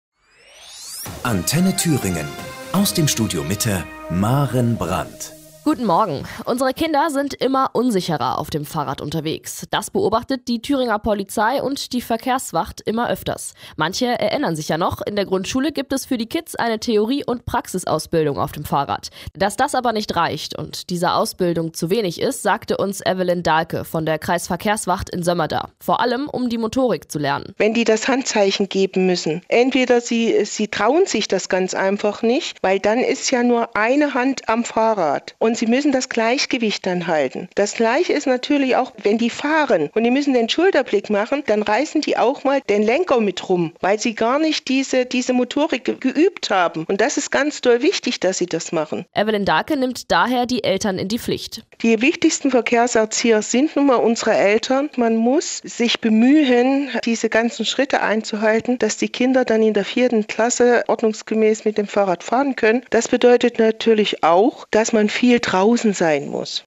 Gastbeitrag der Regionalausgabe von Antenne Thüringen